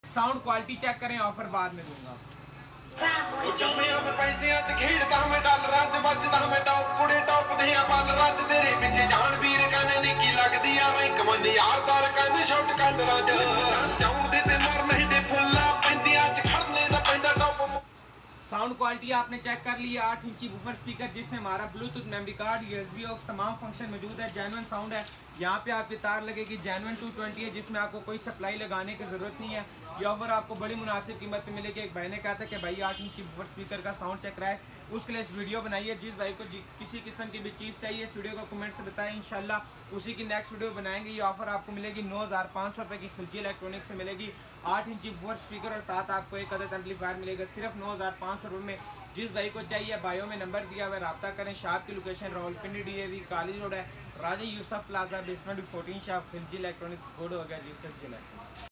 8 Inches Woofer Speaker With Sound Effects Free Download